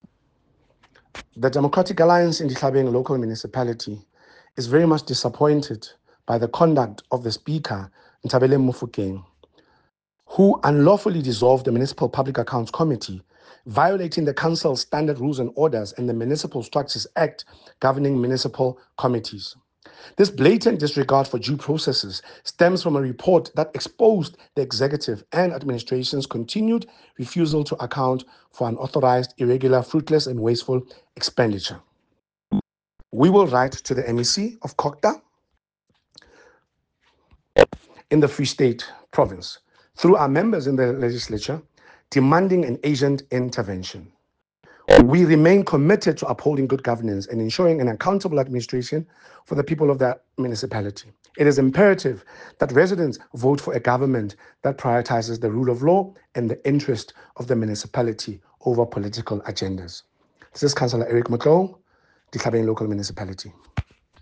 Sesotho soundbites by Cllr Eric Motloung